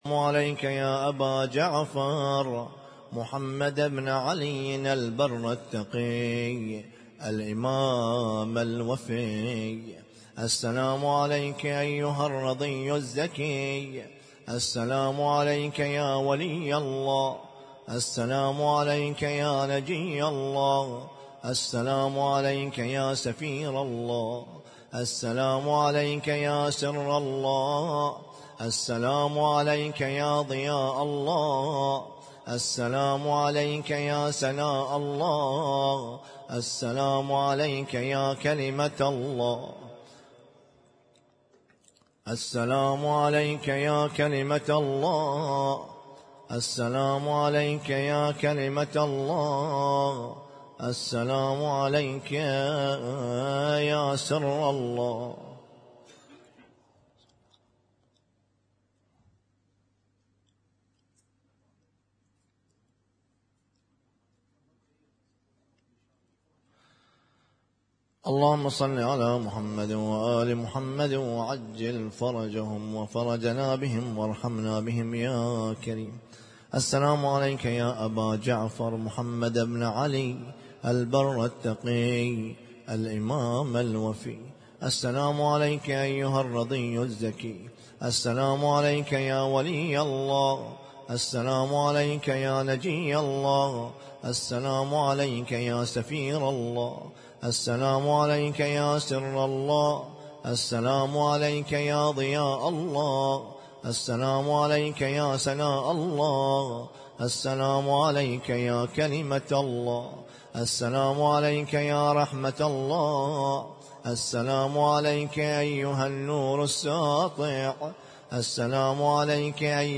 القارئ: الرادود